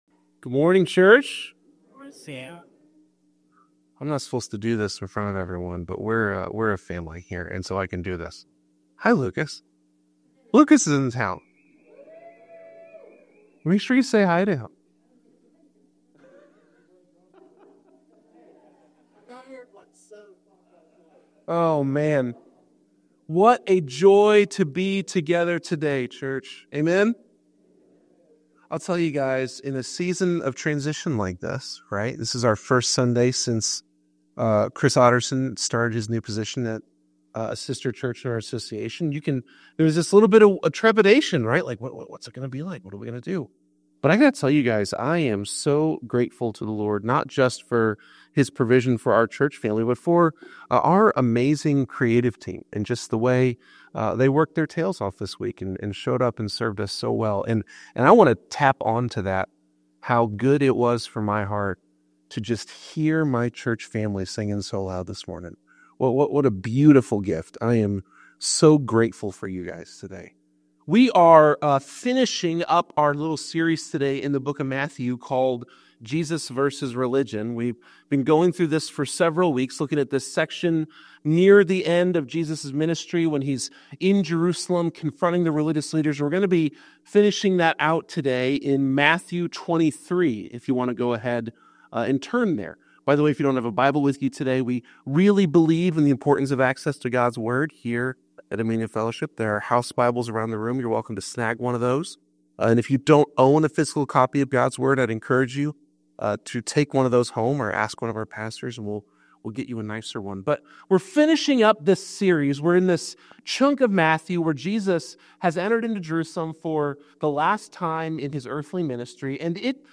This message calls us to turn to Jesus while we can, embracing a faith that truly connects us to God and His eternal kingdom.